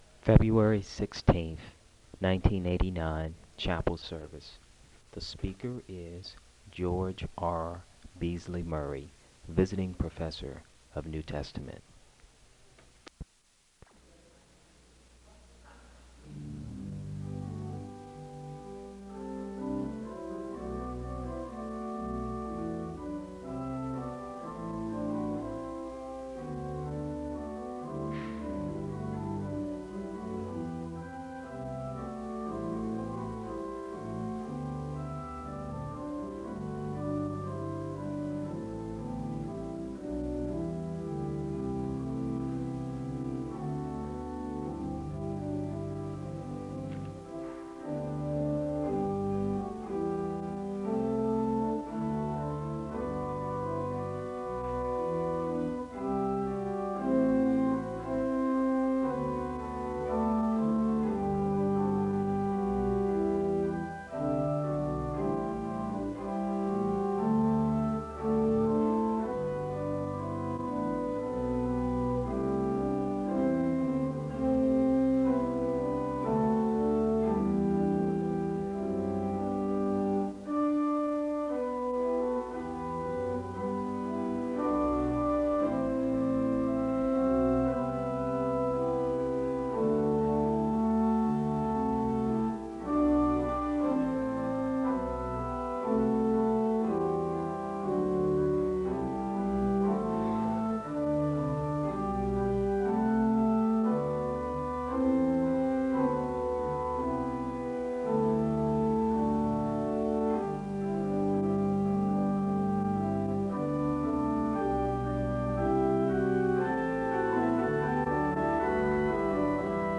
Dr. Beasley-Murray is announced as Chapel speaker (0:00-0:12). A hymn is played (0:13-4:01).
Dr. Beasley-Murray reads Mark 12:28-34 (7:31-10:24). The choir sings an anthem (10:25-12:17).
A word of prayer ends the service (32:33-33:30).